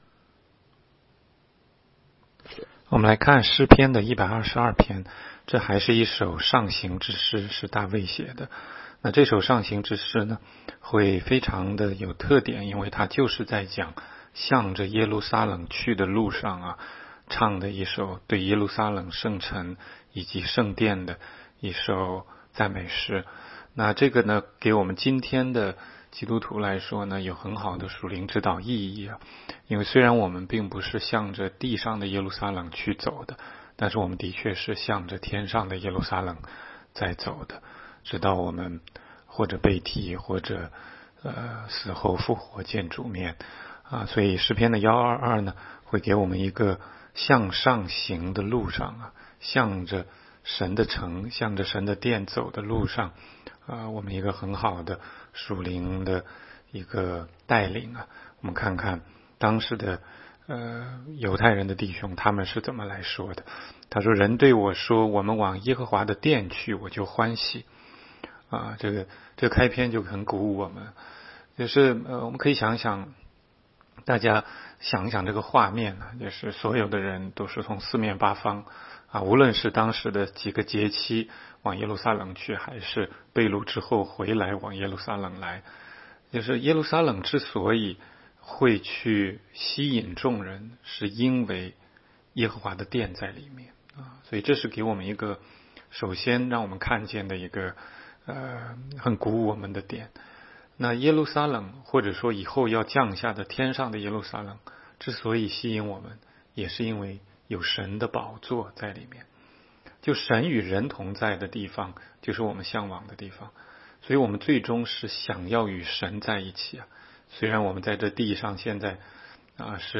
16街讲道录音 - 每日读经 -《 诗篇》122章